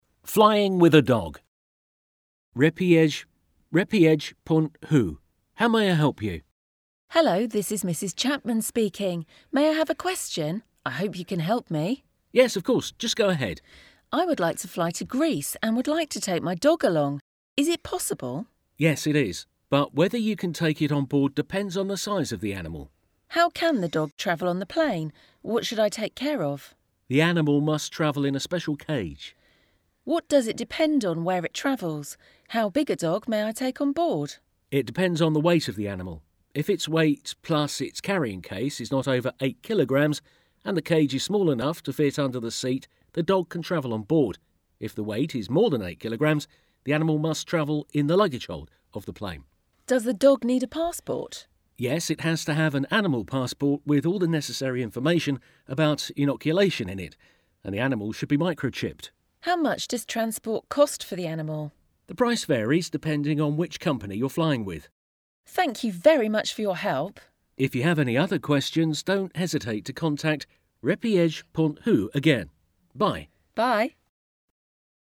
5_Dialogue_Flying_with_a_Dog.mp3